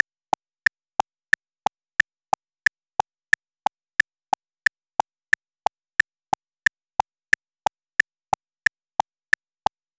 Lo-fi, mid-range frequency, no reverb or tail. 0:01 Sound effect only (not music). Length: 90 milliseconds. One-shot. Sound type: UI click. Envelope: instant attack, no tail. Pitch: neutral mid-high. Texture: clean digital tick. Bass: none. Mood: neutral, functional. Dry sound, no reverb. 0:10 A single, short muted pop sound, like a soft, fleshy finger snap.
sound-effect-only-not-mus-wxw4fk6i.wav